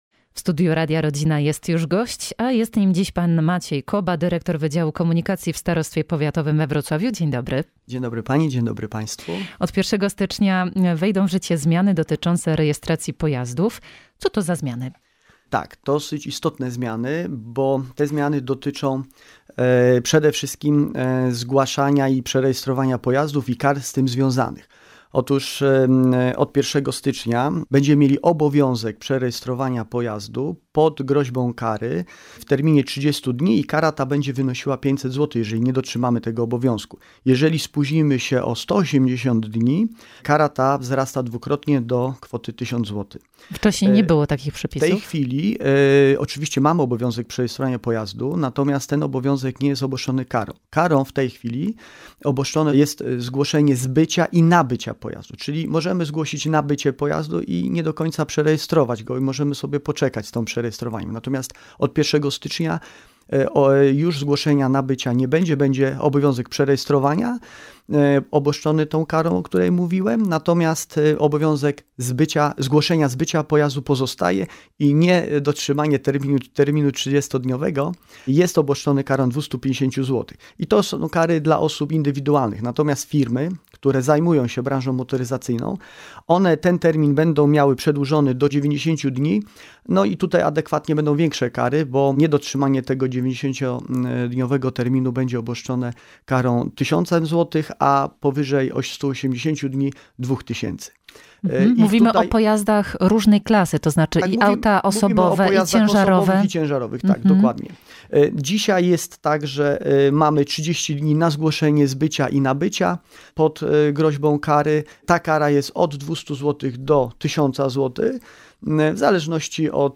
Rozmowa na antenie w czwartek 30 listopada po godz. 14:10.